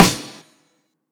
Snares
Sgh_Snr.wav